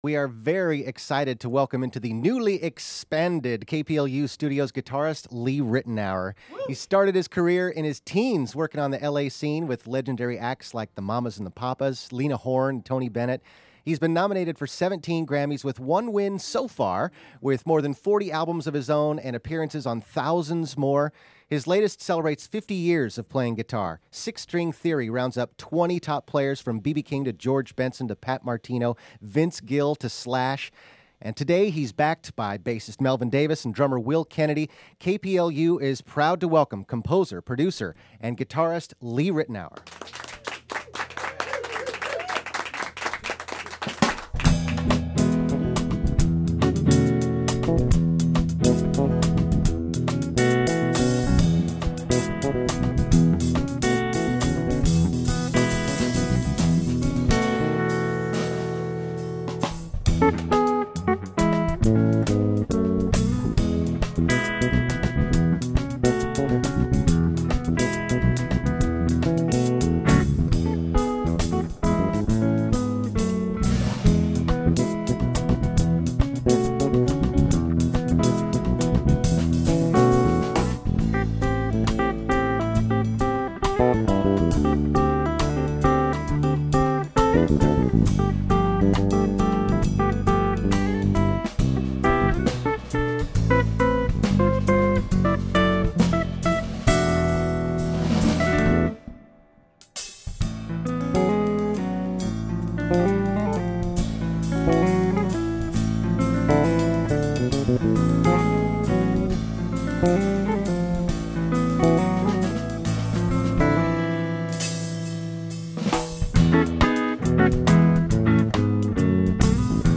Together, they laid down three delightful tracks.
Jazz guitarist